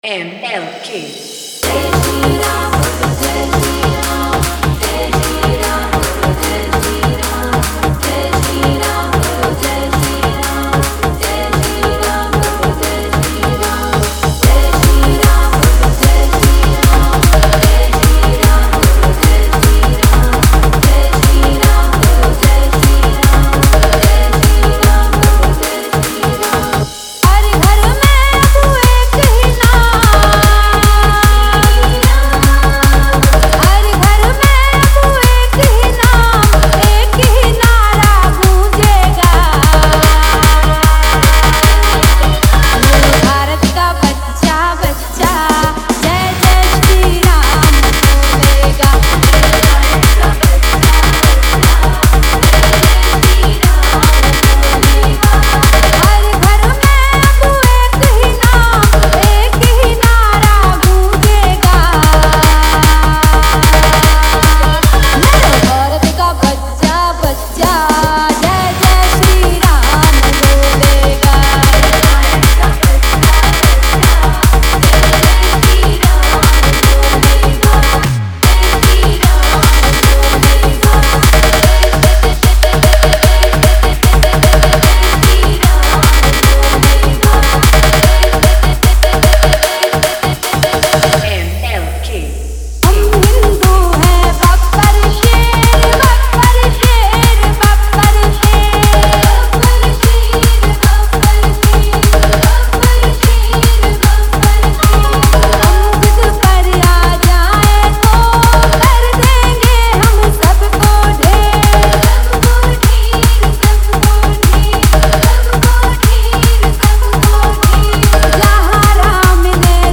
Bhajan Dj Song Collection 2022 Songs Download